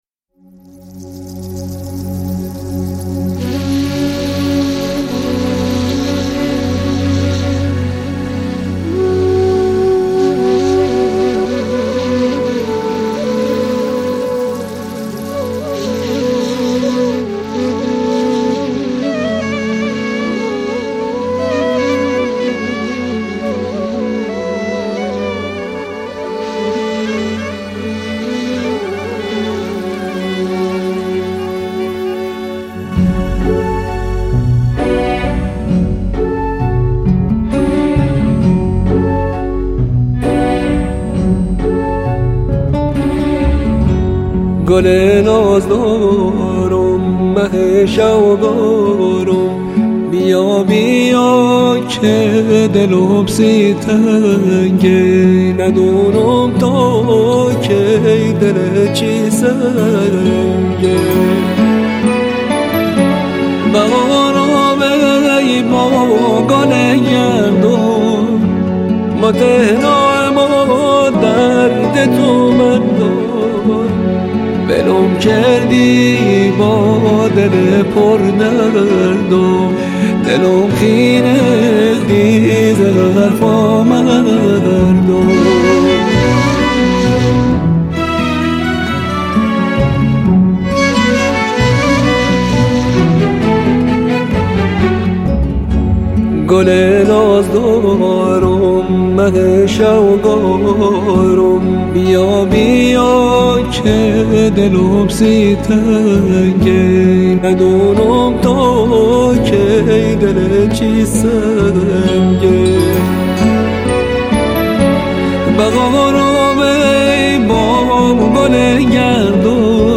ترانه لری بختیاری